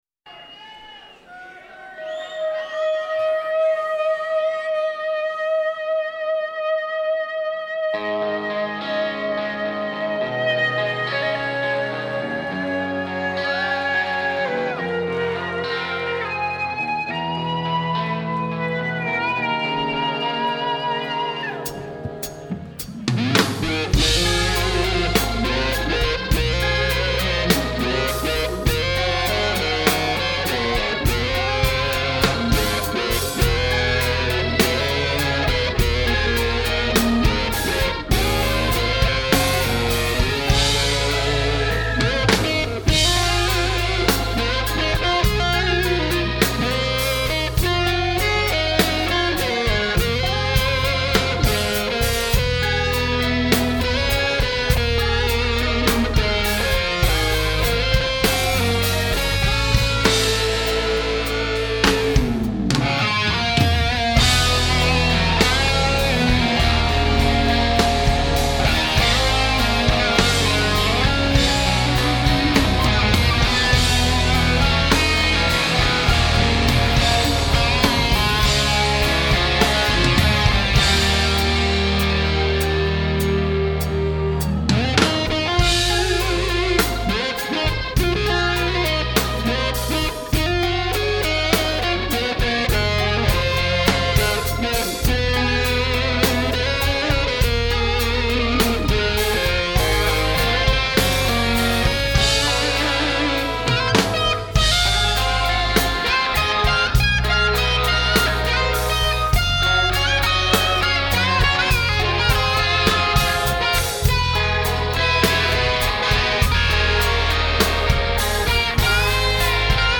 lyrical, almost jazzy lead guitar figures
live in Austin Texas in October 2011